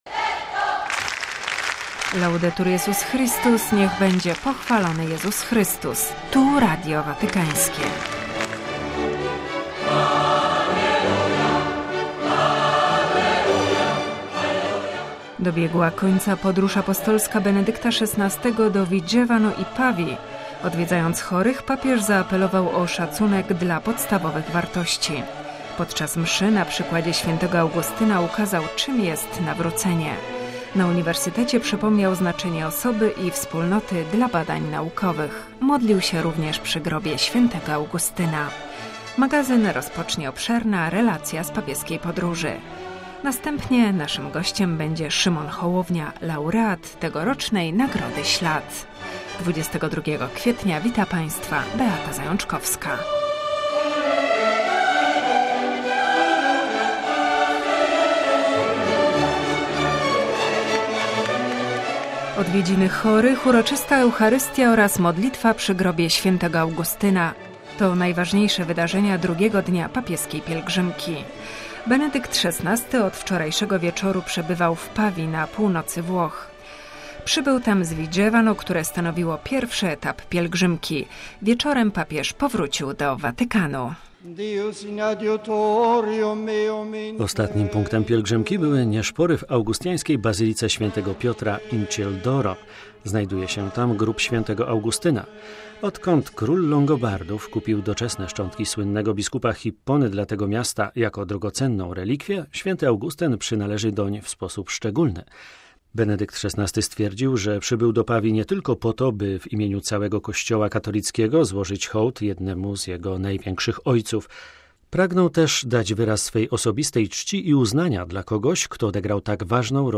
relacja z pielgrzymki Benedykta XVI do Vigevano i Pawii